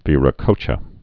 (vērə-kōchə)